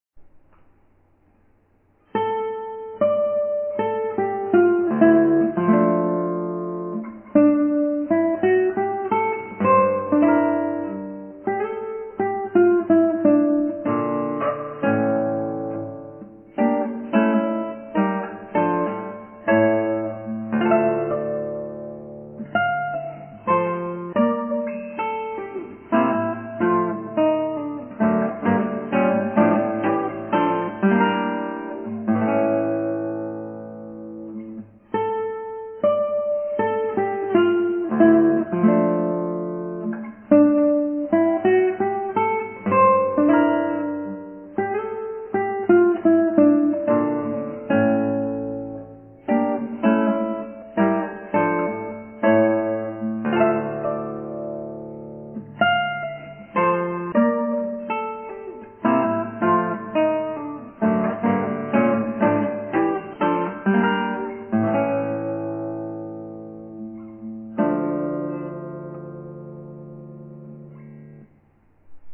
エンデチャ、マリンで